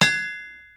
hammerhitsoft.ogg